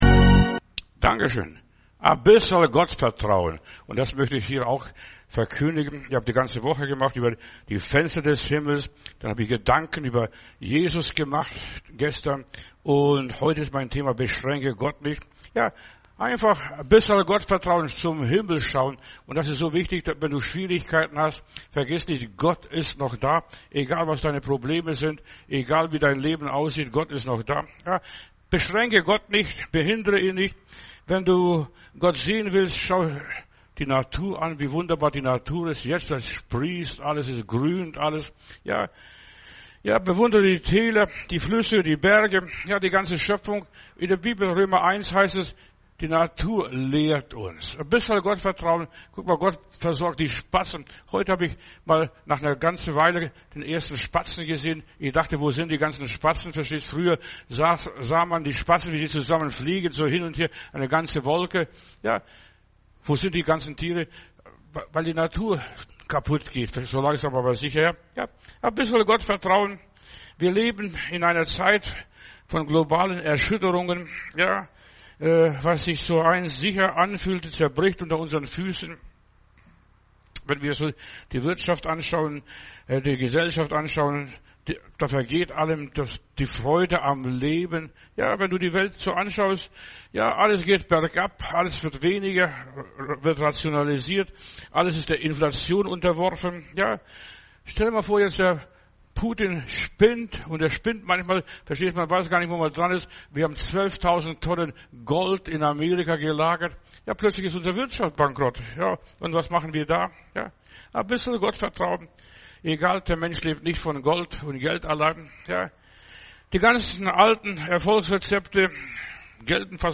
Predigt herunterladen: Audio 2025-06-07 Beschränke Gott nicht Video Beschränke Gott nicht